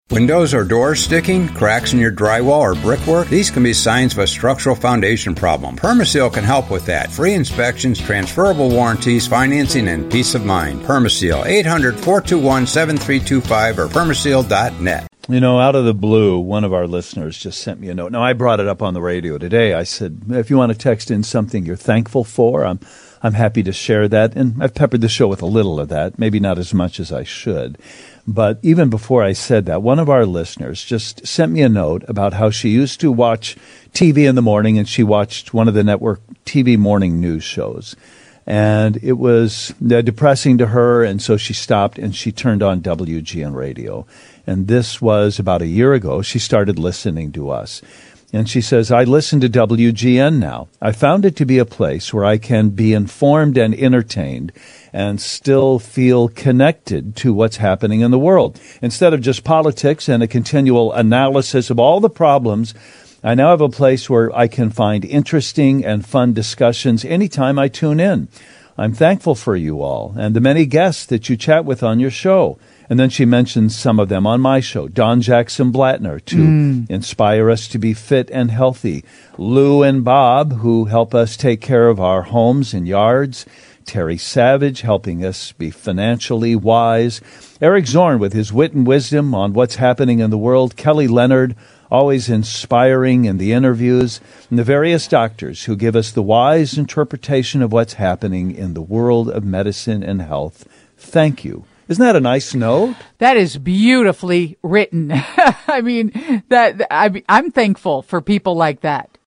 reads a letter of gratitude from a listener